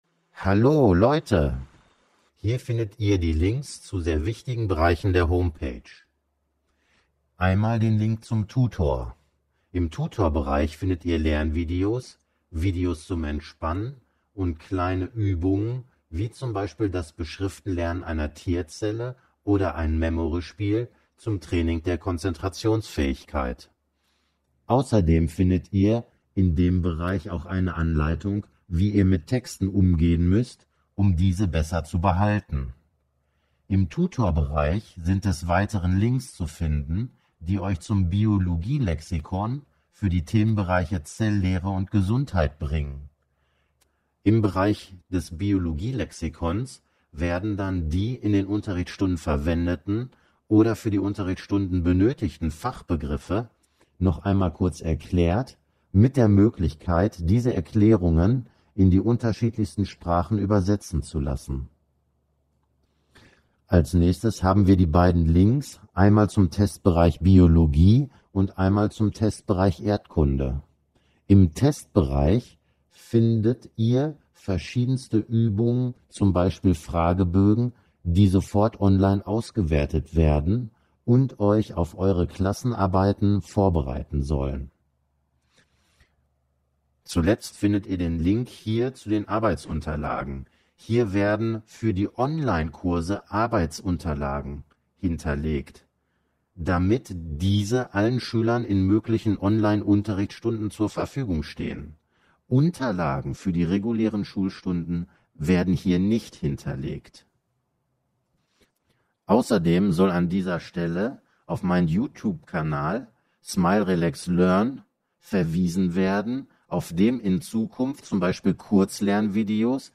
Der vorgelesene Text der Erklärungen zu den Links!